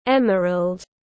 Màu xanh lục tươi tiếng anh gọi là emerald, phiên âm tiếng anh đọc là /´emərəld/.